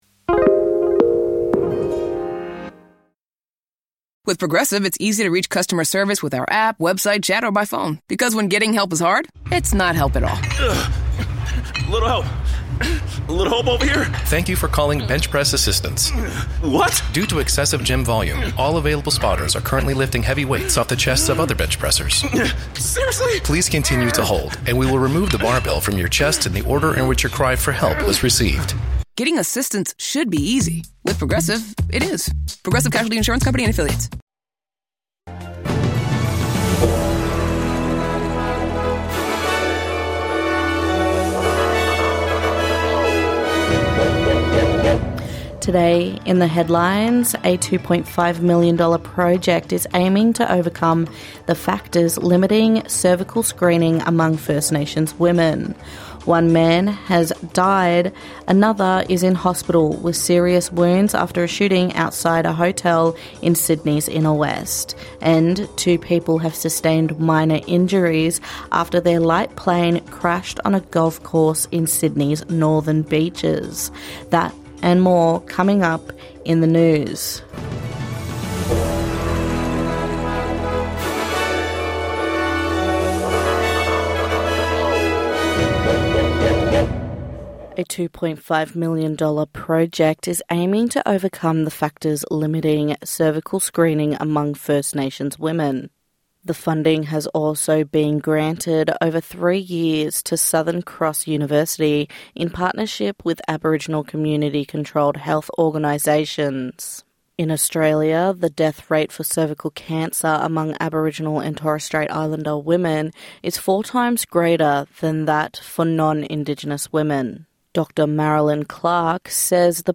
NITV Radio reports the latest in the news, sport and weather along with stories from community and across the country.